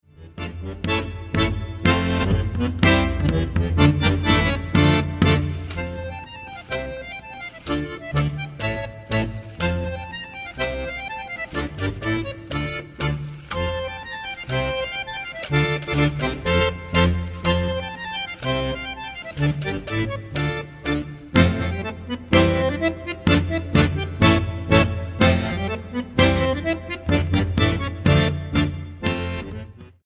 acordeón clásico
vihuela y guitarra renacentistas
percusiones
guitarra española y percusiones